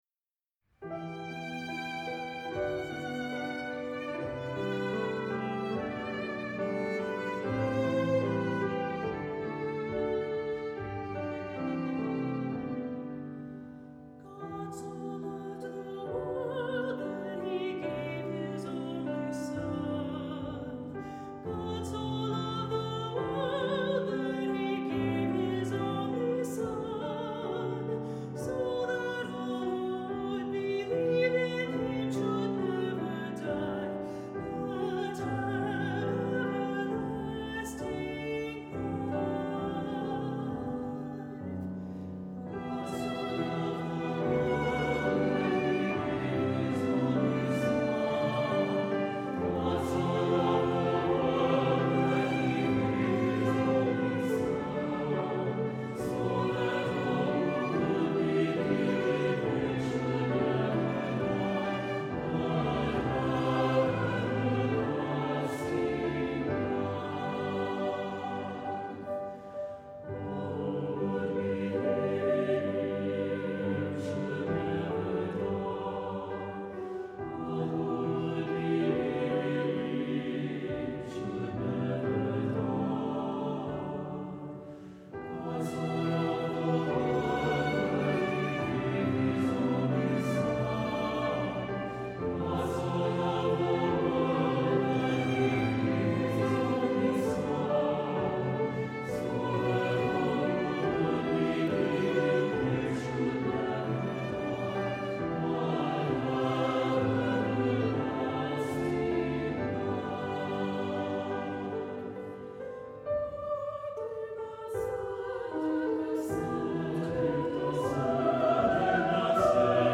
Voicing: SATB, Optional Congregation